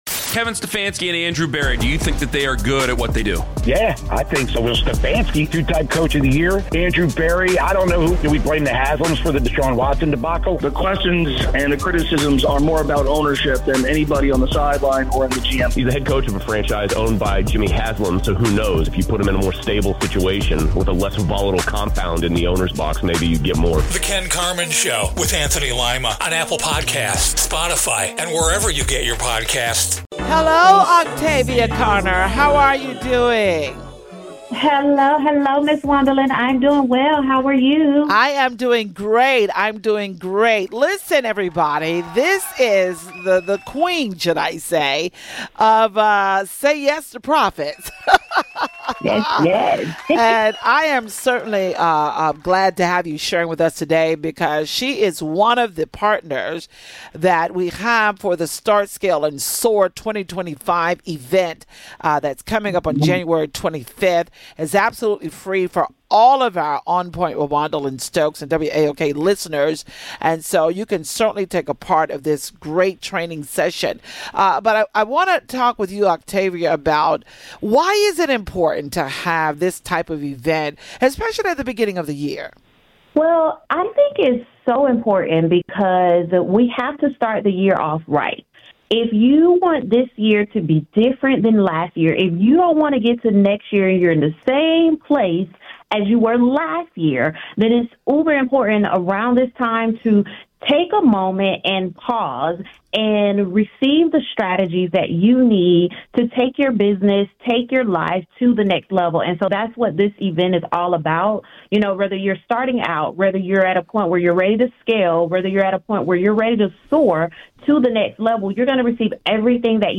Financial Wellness conversation